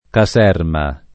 ka@$rma o